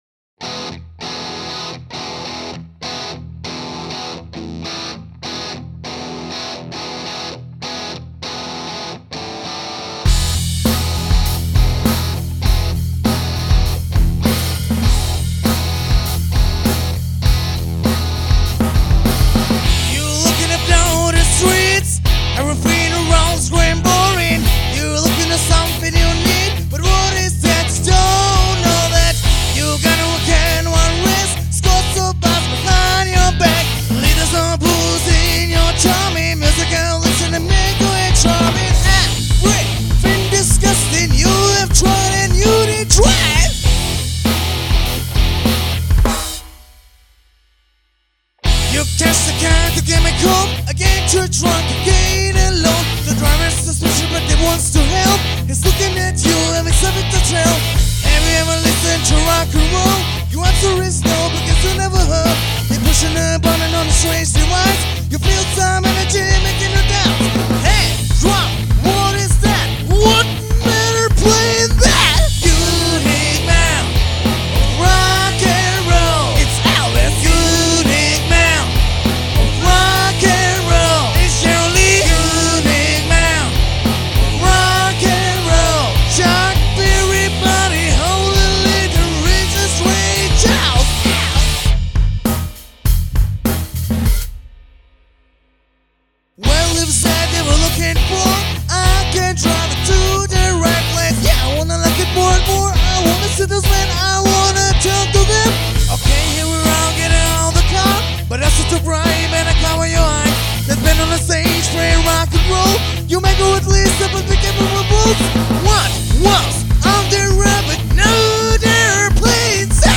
О себе: Рок-группа, играет качественный Hard Rock'N'Roll.